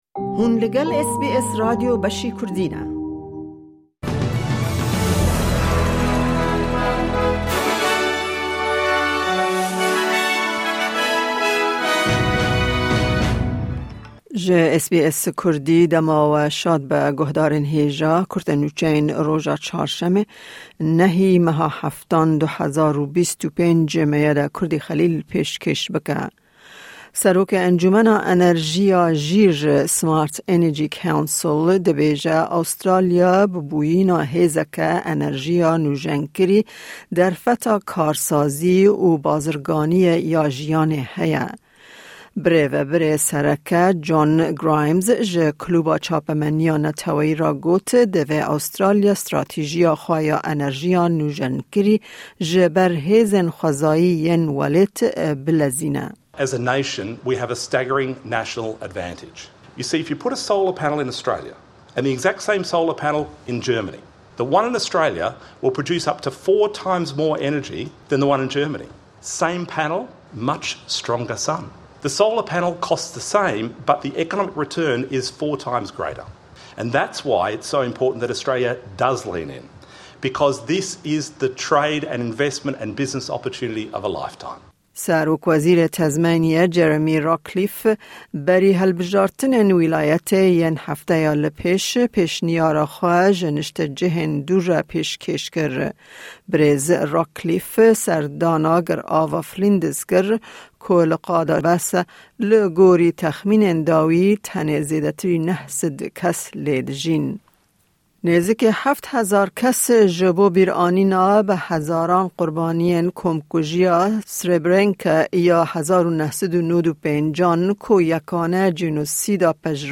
Kurte Nûçeyên roja Çarşemê, 9î Tîrmeha 2025